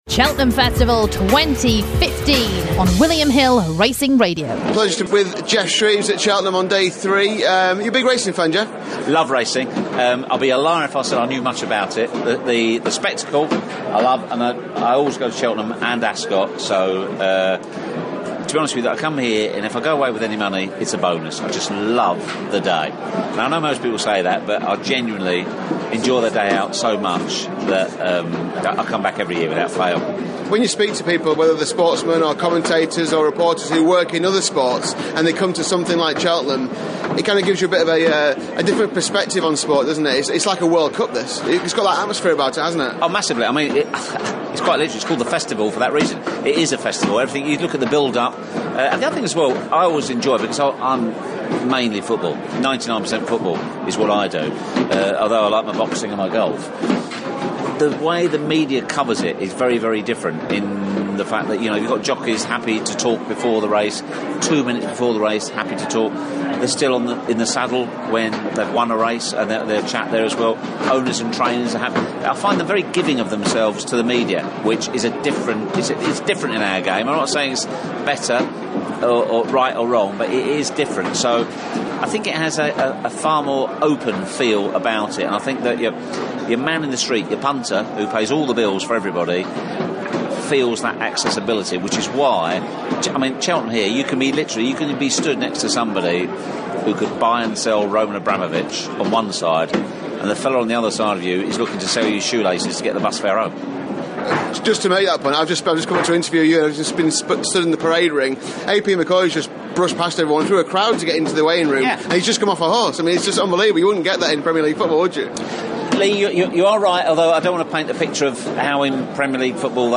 Sky Sports Geoff Shreeves talks to William Hill about the NSPCC Tipping Challenge, the Cheltenham Festival and the English clubs in Champions League action.